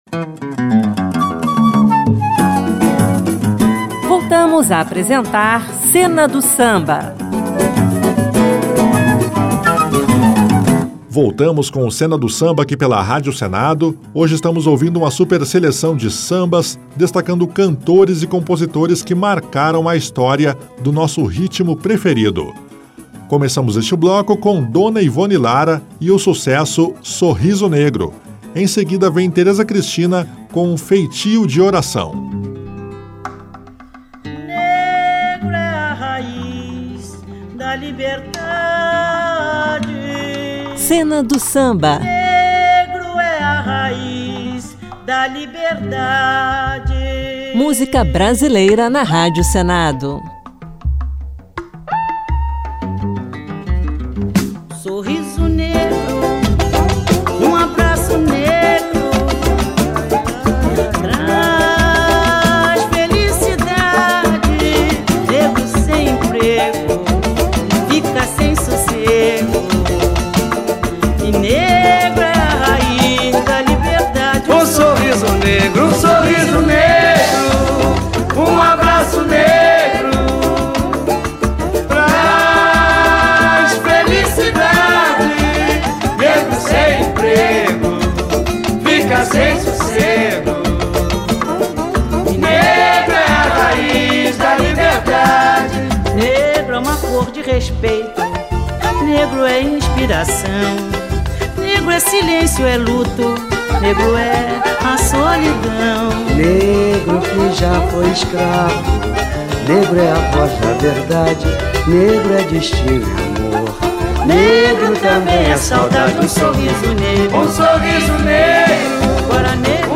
Tópicos: Samba